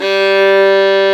STR FIDDLE00.wav